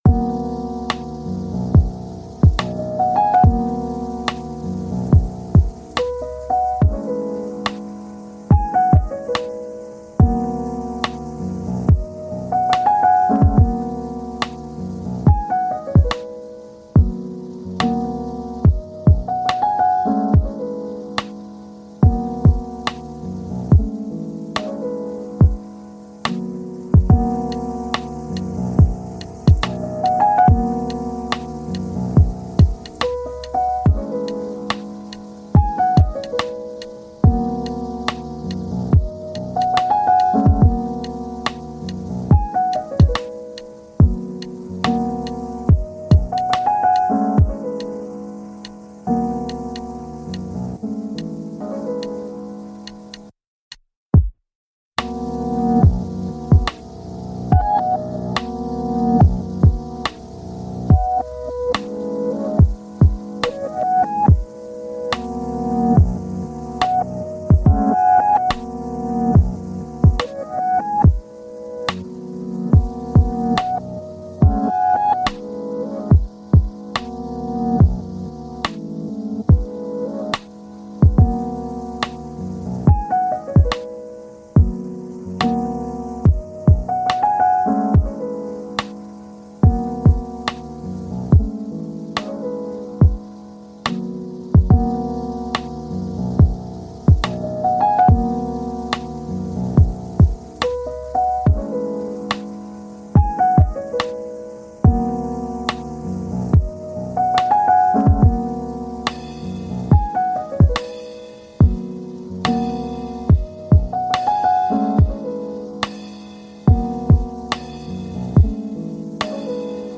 Beethoven Piano : Concentration Profonde